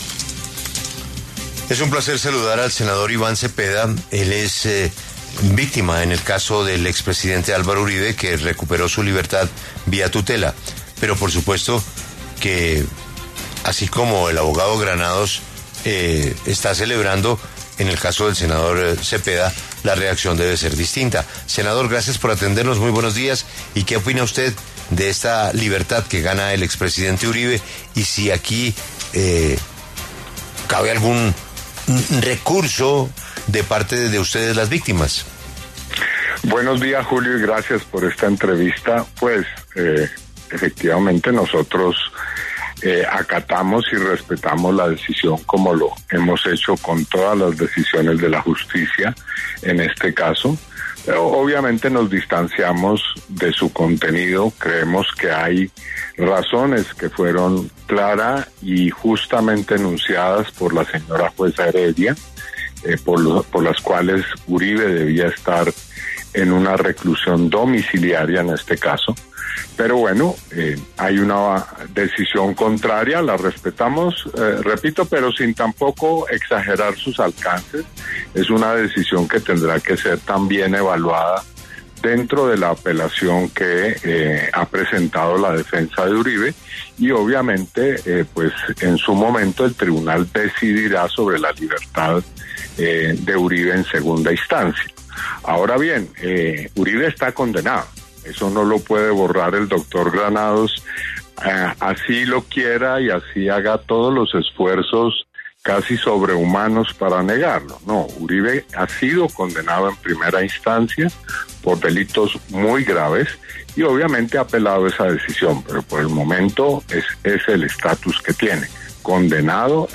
El senador Iván Cepeda conversó con La W sobre la decisión del Tribunal Superior de Bogotá de dejar en libertad a Álvaro Uribe Vélez mientras se resuelve segunda instancia.